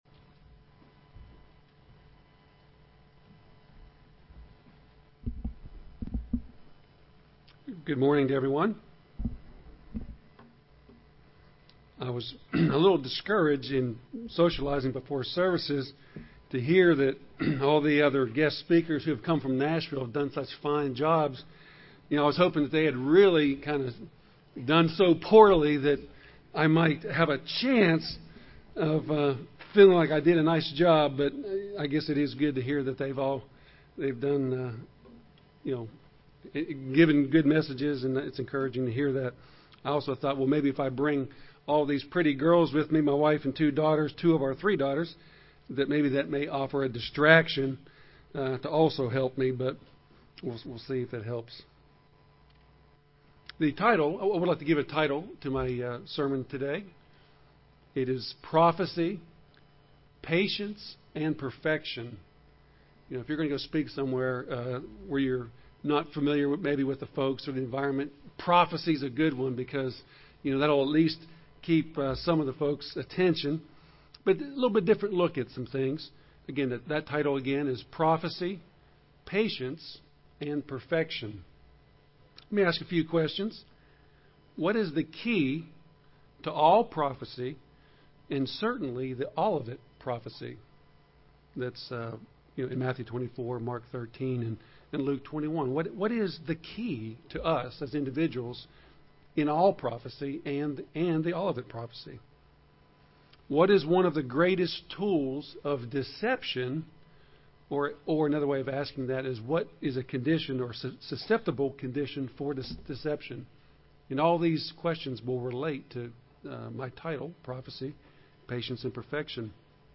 Given in Rome, GA
UCG Sermon